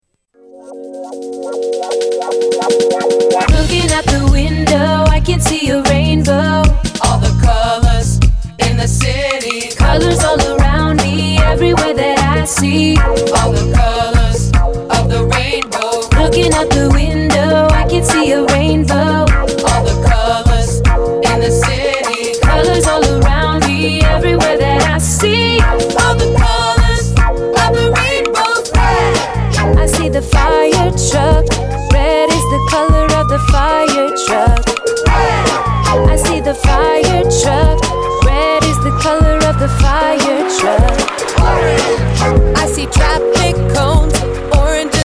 Rap and sing about counting, friendship, shapes and coins